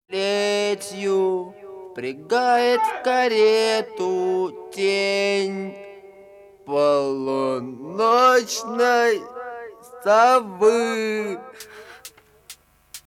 с характерными гитарными рифами и запоминающимся вокалом.